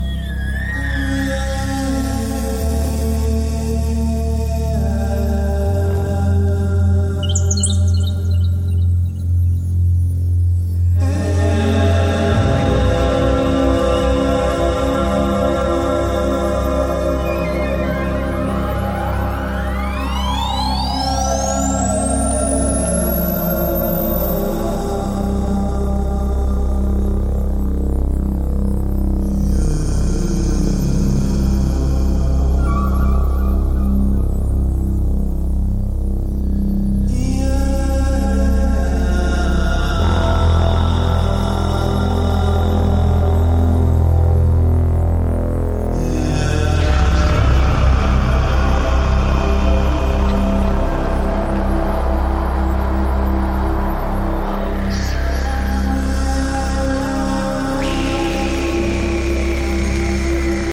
壮大なサウンドスケープは、忙しない日常の心の支えとなるメディテーションに没入できます。